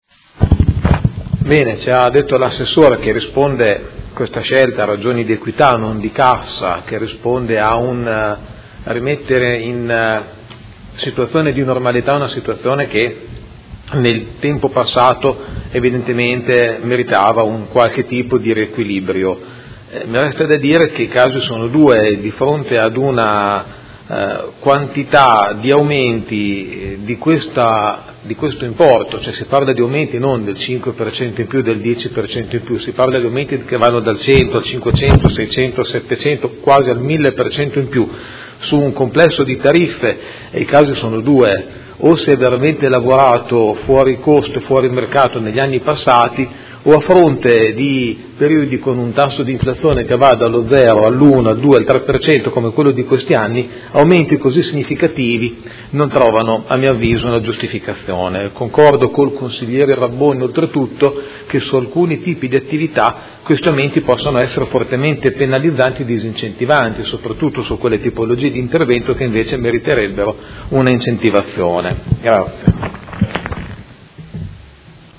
Seduta del 10/03/2016. Dibattito sui interrogazione del Consigliere Pellacani (F.I.) avente per oggetto: Aumento tariffe comunali del Settore Urbanistico